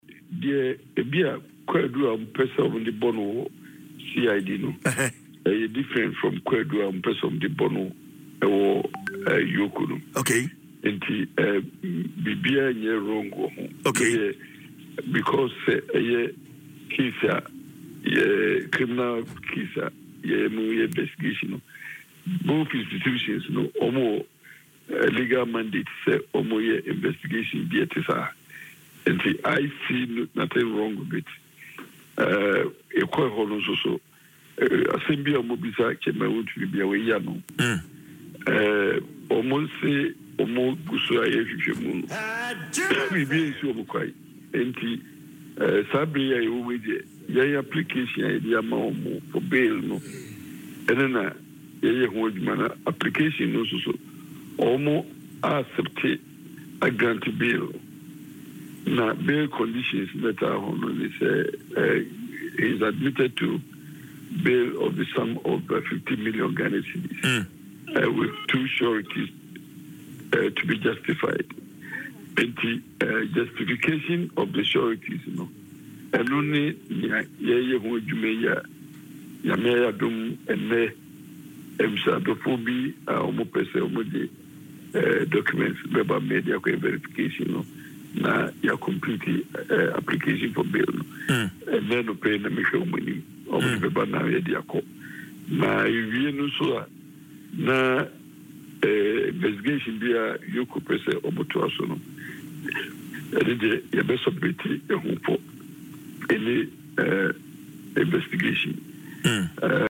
However, lawyer Appiah-Kubi in an interview on Adom FMs morning show Dwaso Nsem, stated that the charges from the CID may differ from those filed by EOCO.